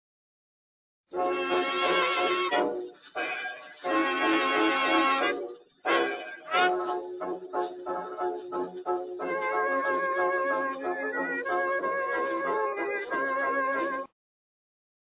Lots of original razzamatazz fun from the Roaring Twenties.